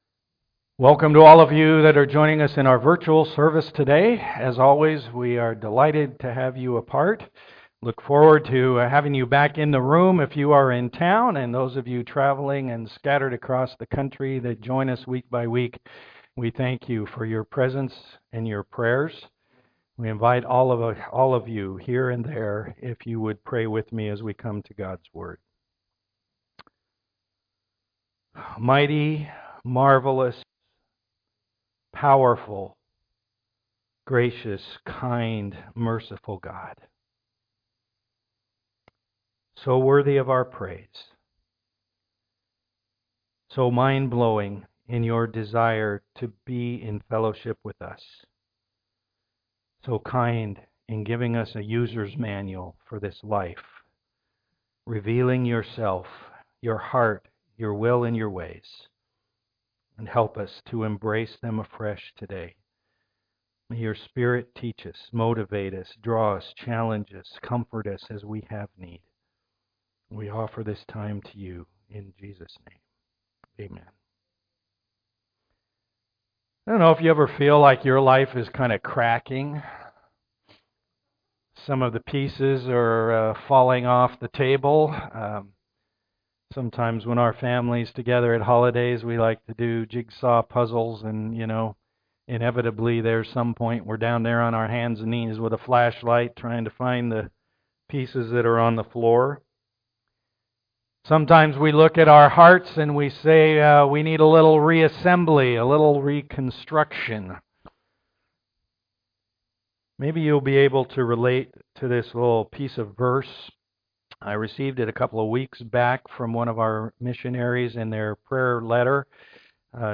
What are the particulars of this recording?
Romans 12:3-8 Service Type: am worship The "living sacrifice" life plays out in the life of the body of Christ.